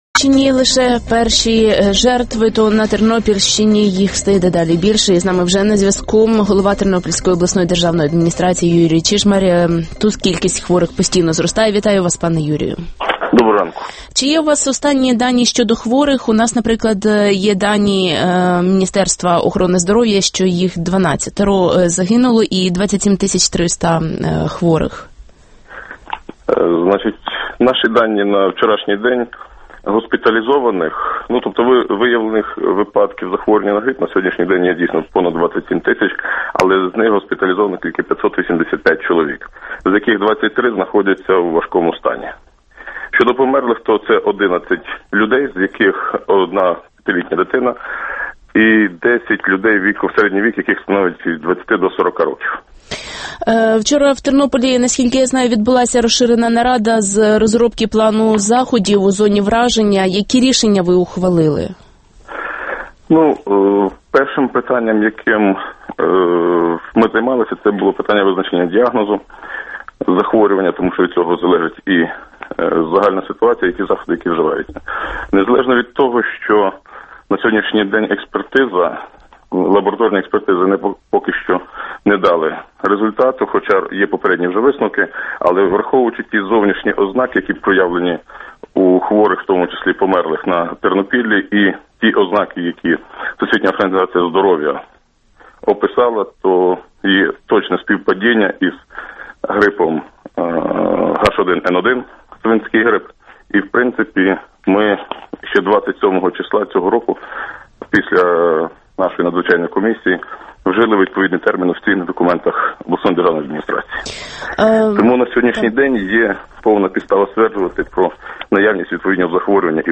Голова Тернопільської адміністрації Юрій Чижмарь – включення